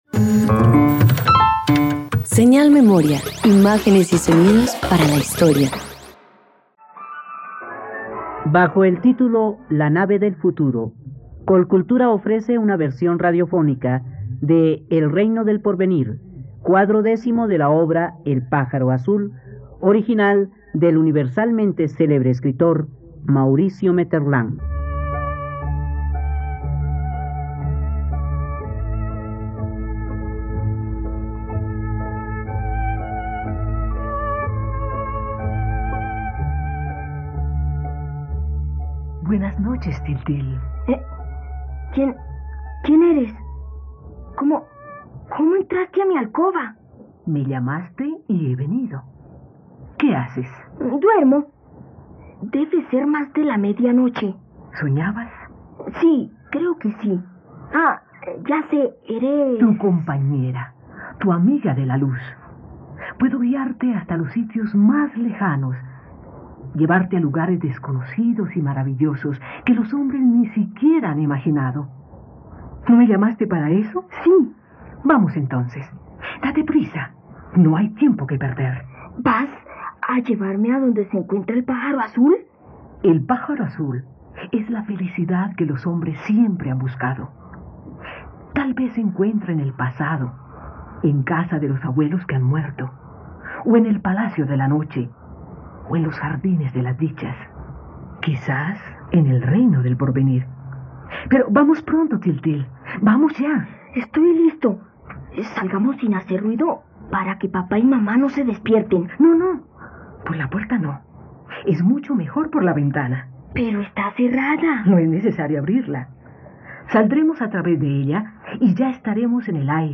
La nave del futuro - Radioteatro dominical | RTVCPlay